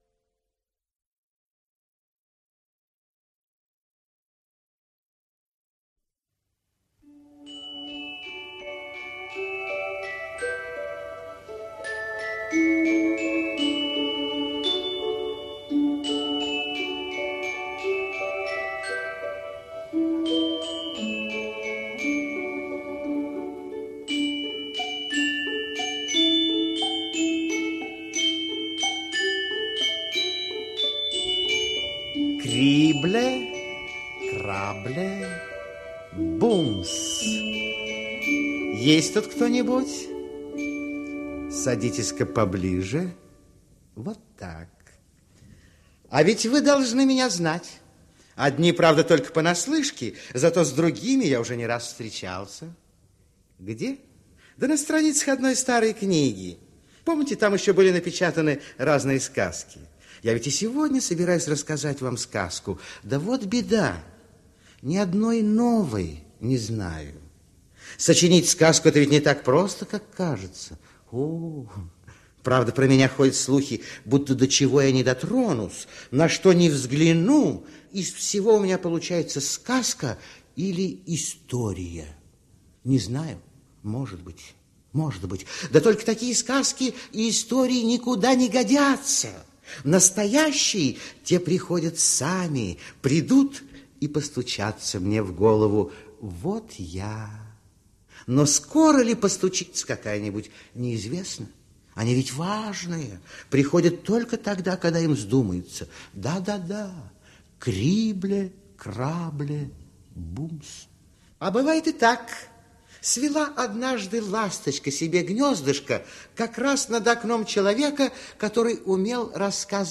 Аудиокнига Свинопас.